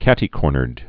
(kătē-kôrnərd) or cat·ty-cor·ner (-nər)